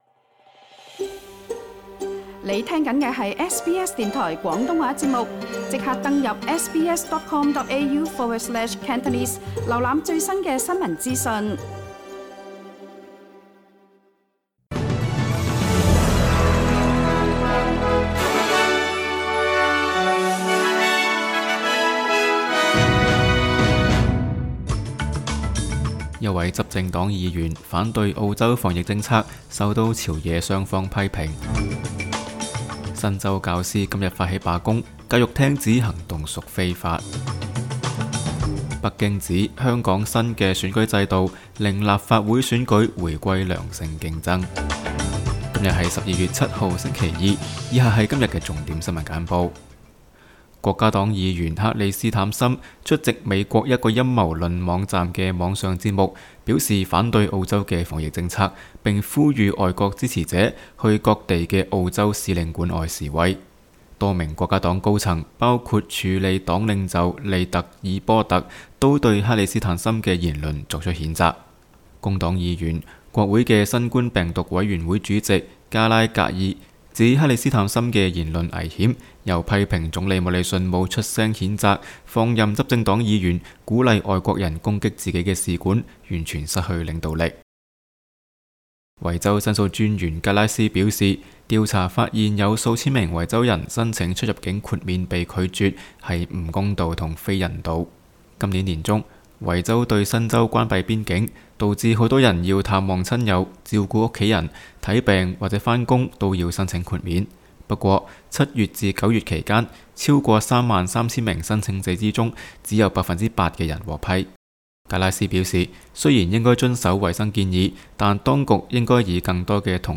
SBS 新聞簡報（12月7日）
SBS 廣東話節目新聞簡報 Source: SBS Cantonese